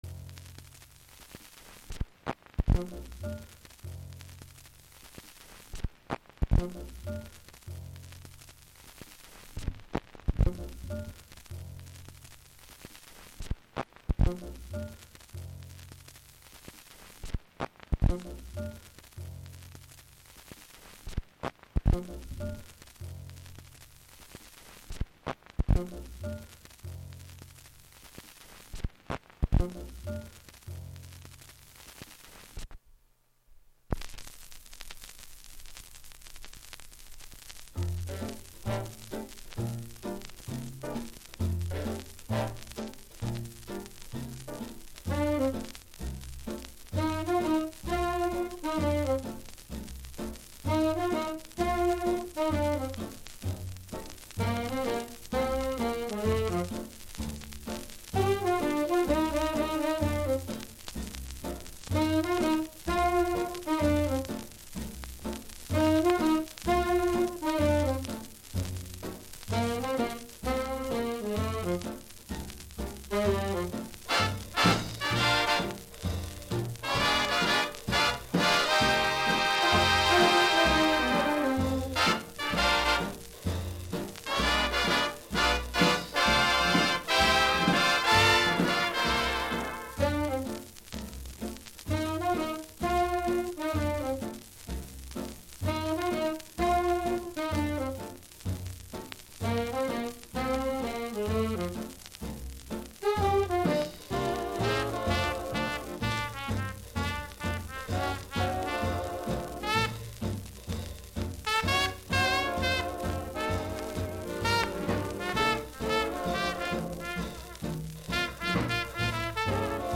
i play old scratchy records